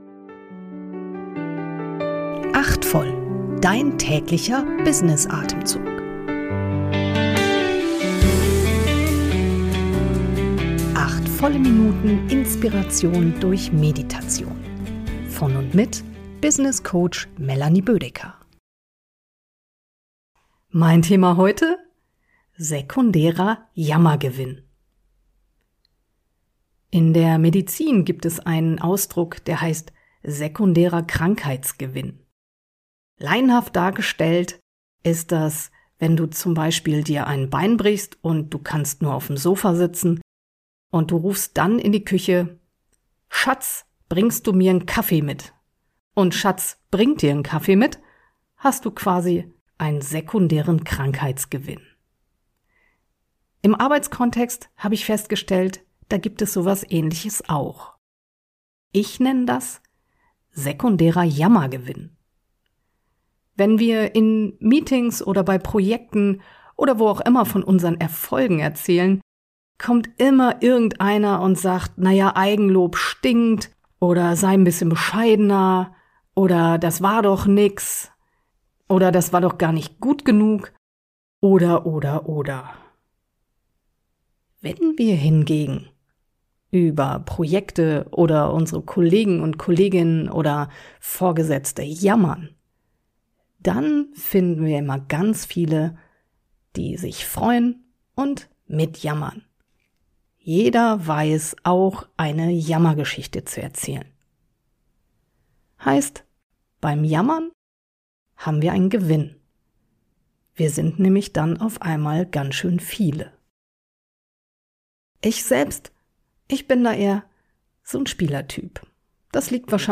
geleitete Kurz-Meditation.